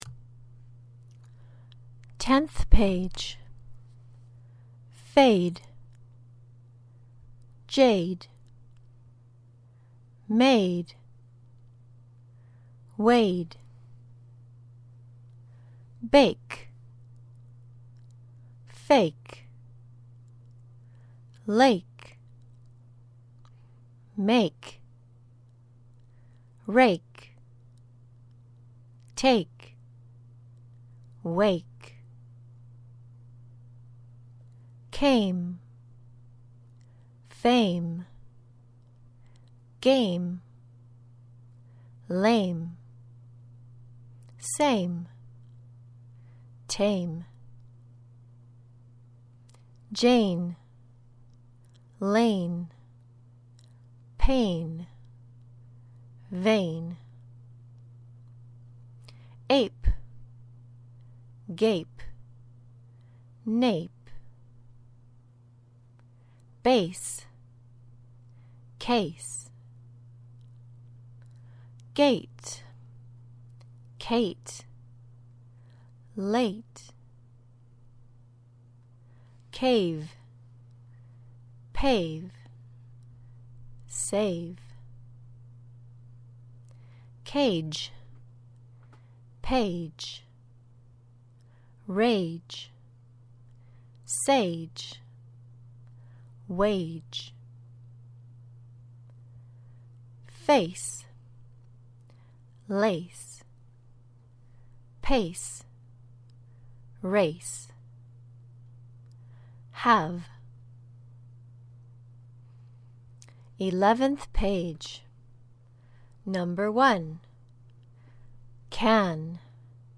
Practice the pronunciation of words ending in a silent ‘e.’